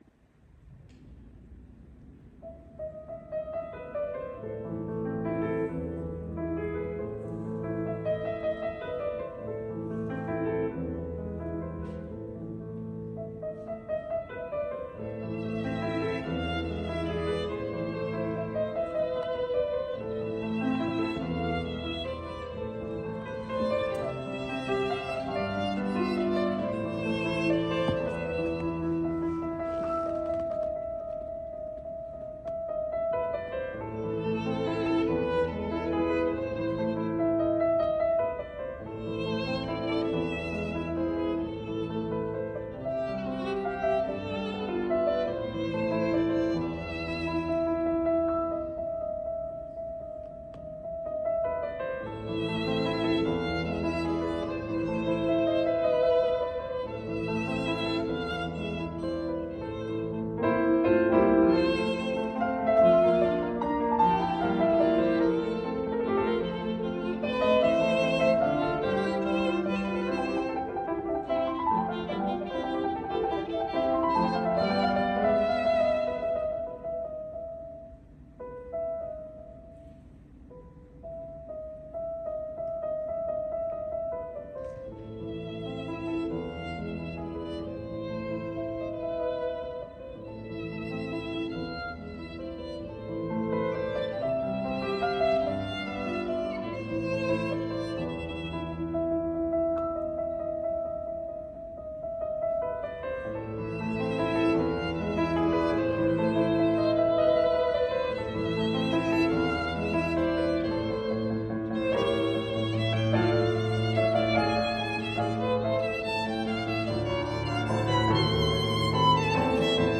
The Premier Performance
This composition re-imagines Beethoven’s Für Elise with a violin accompaniment composed by the Anticipatory Music Transformer: a generative model of music.